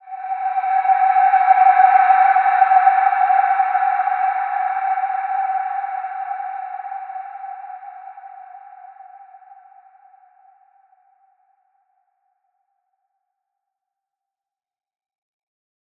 Large-Space-G5-f.wav